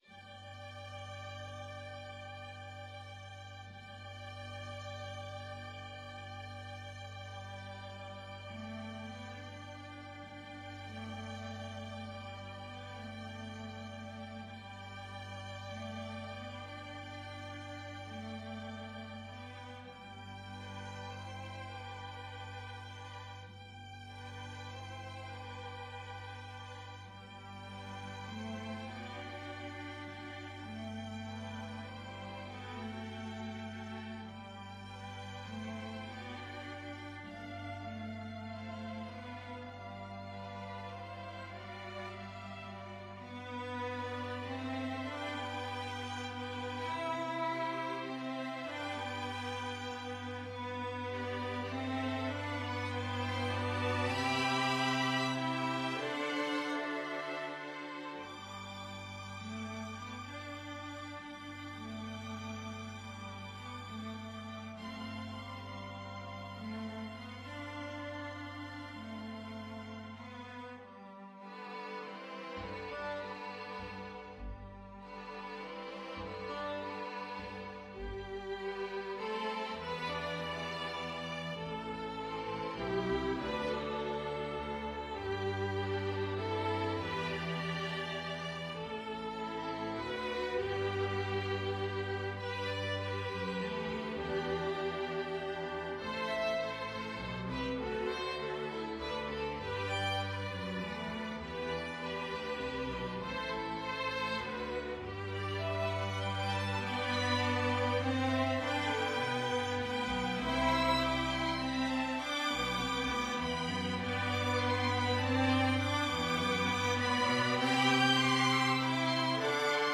Violin 1Violin 2ViolaCelloDouble Bass
3/4 (View more 3/4 Music)
Gently. In the manner of a lullaby ( = c. 100)